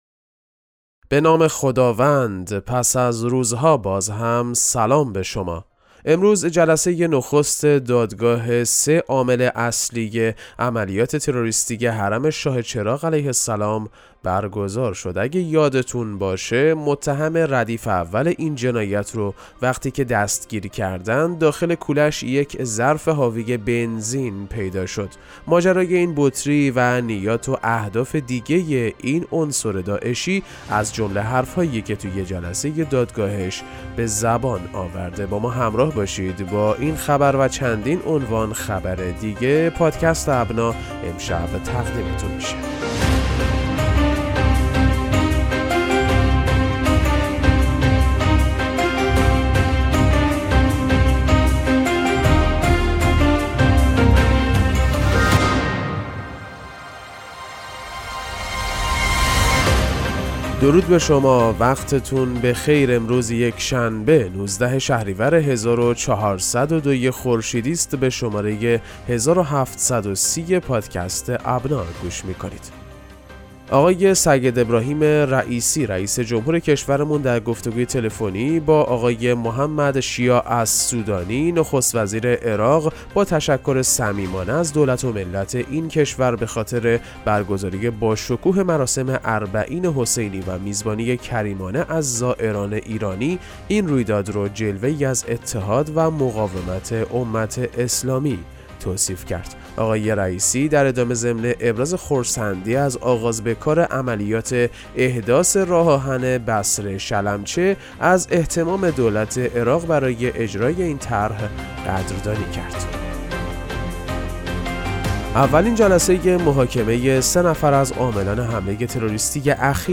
پادکست مهم‌ترین اخبار ابنا فارسی ــ 19 شهریور 1402